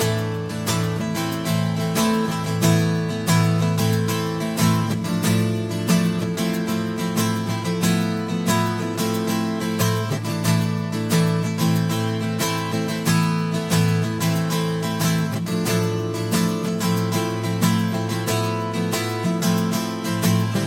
гитара
Завораживающие
инструментал
Завораживающая мелодия